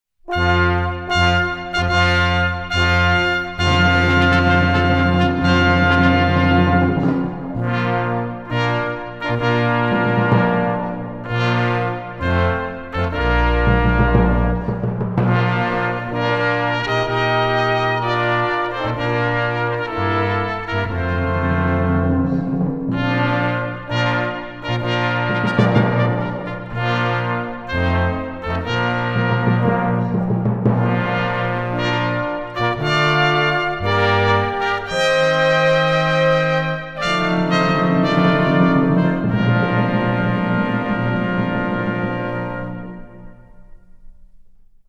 Seaside Brass - Classical Repertoire - mp3's: